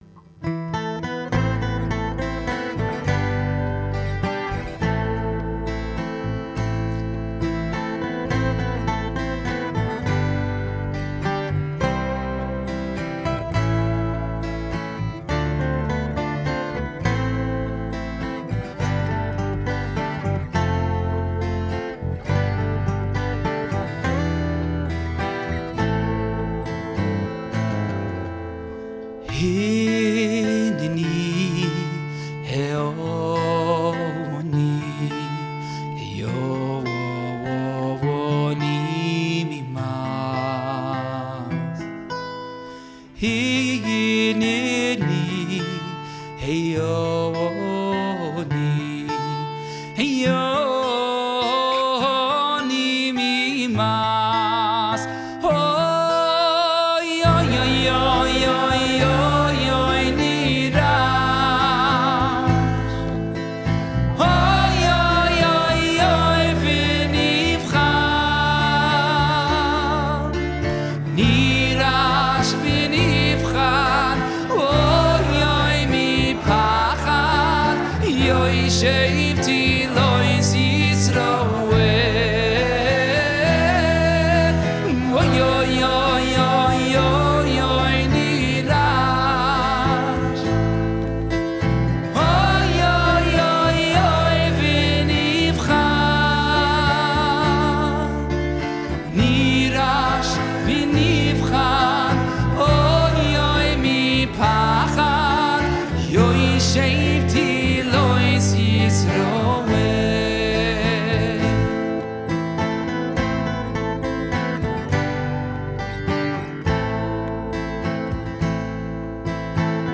It captures the reverence, the yearning, and the inner stillness of a soul standing before its Creator.
Pre Selichos Kumzitz 5785 - Hineni.